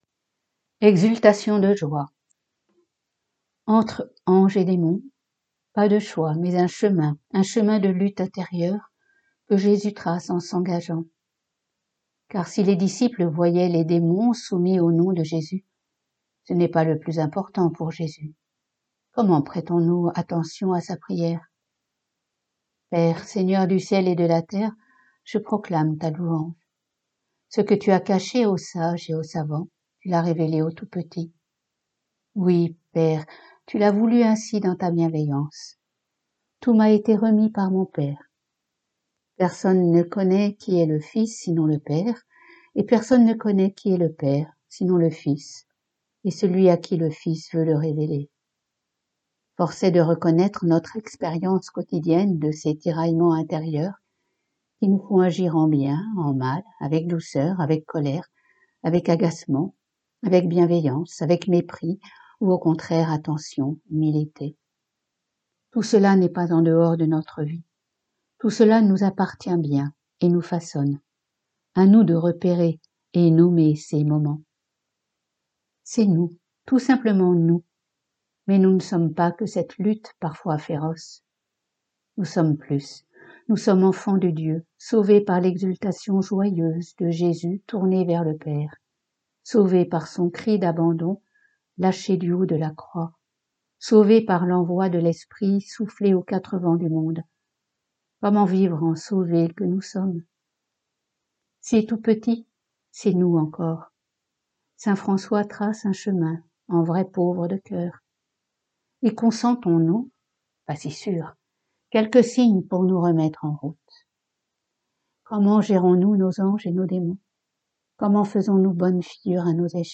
pour chaque billet mentionné ci-dessous, un lien vers le fichier-son enregistré au monastère, suivi d’un lien vers le billet en texte (mise à jour fréquente)